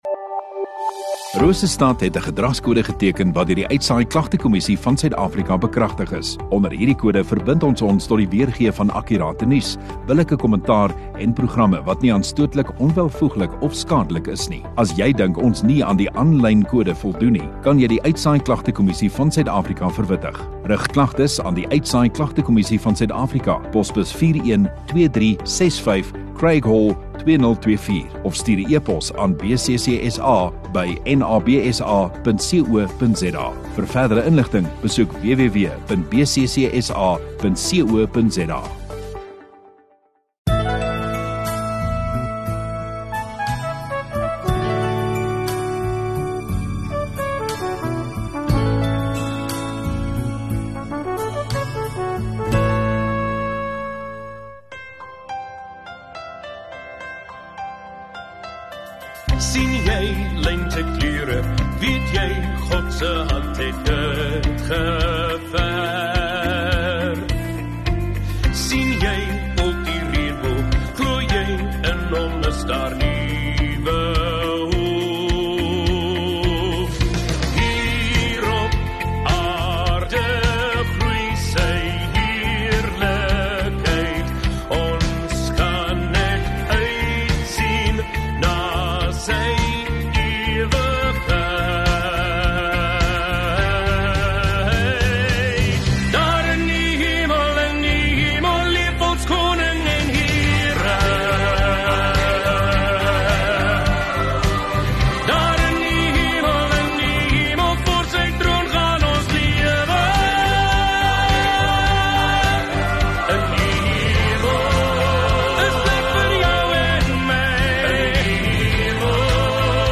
29 May (Hemelvaartsdag) Donderdagoggend Erediens